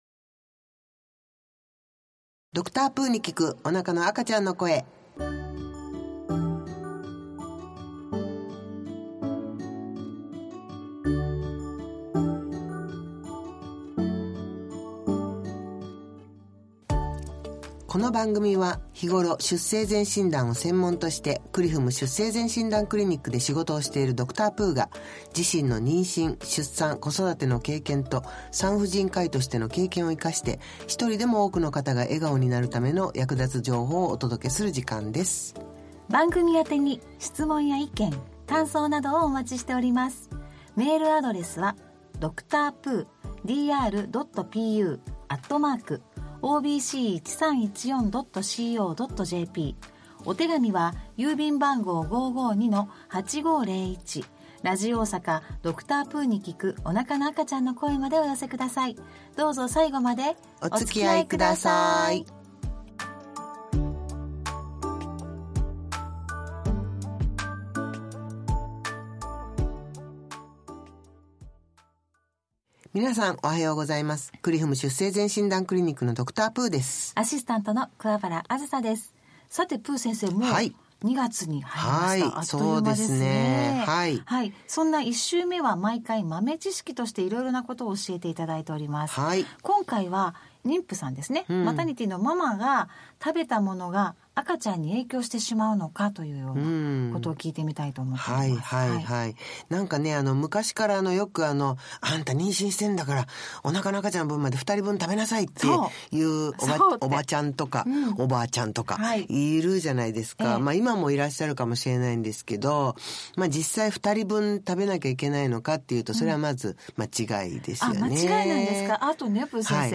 おなかの赤ちゃんの声』第148回が放送されました メディア 2026/2/1(日)放送 第148回 再生ボタンを押すと、 実際の放送 をお聴きいただけます。